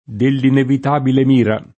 Moira [ m 0 ira ] pers. f. mit.